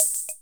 Machine_Hatz.wav